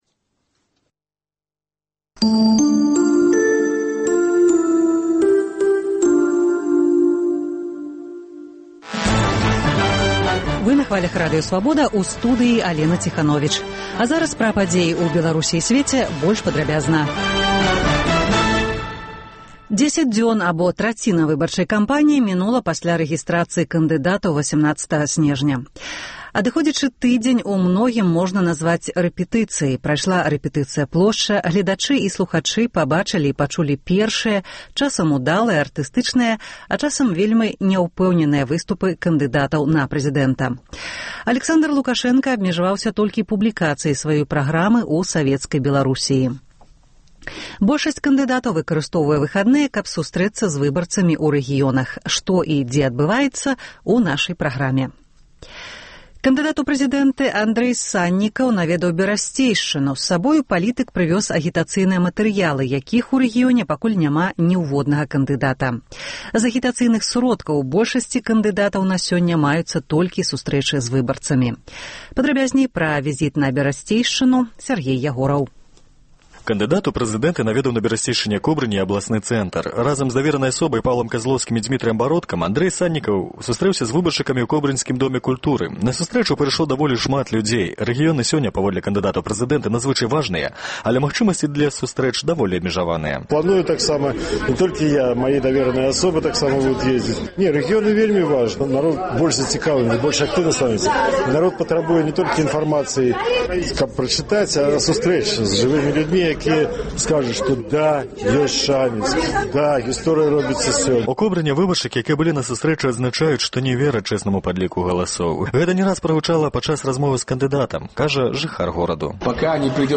Normal 0 Паведамленьні нашых карэспандэнтаў, званкі слухачоў, апытаньні ў гарадах і мястэчках Беларусі